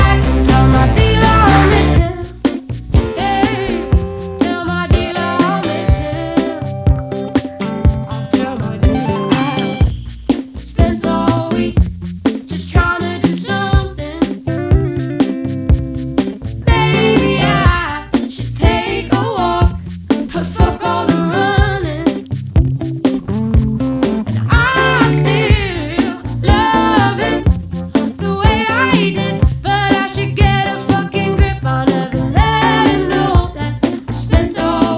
Жанр: Альтернатива Длительность